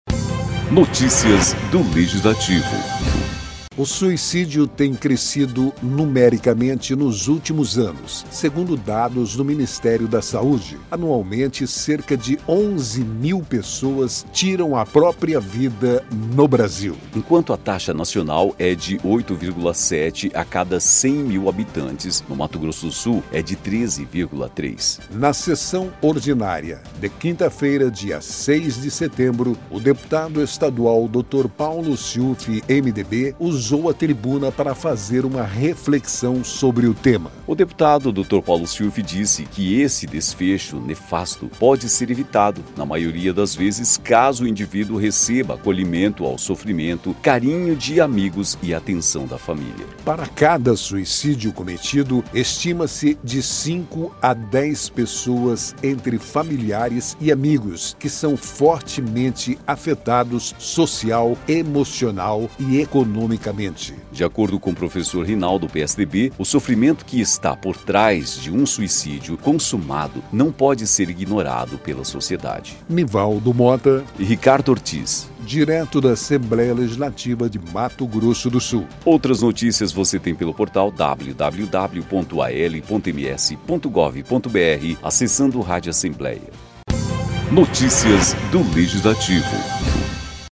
Na sessão ordinária desta quinta-feira (6), o deputado estadual Dr. Paulo Siufi (MDB) usou a tribuna para fazer uma reflexão sobre o tema.